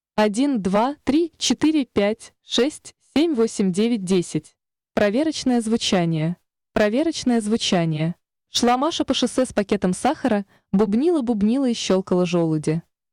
Сформировал в яндекс переводчике звуковой файл, в котором сделал много шипящих, свистящих и бубнящих звуков, закинул его на плейер. Плейер подключил к SSM, а выход SSM к линейному входу звуковой карты компьютера. На компе звук записывал с помощью Adobe Audition.
4. Резистор 200кОм = 200кОм, компрессия больше 10:1 (по даташиту 10:1 при 175кОм):
Как мне кажется, выше 5:1 делать не стоит, т.к. при компрессии 10:1 уже отчётливо слышны искажения.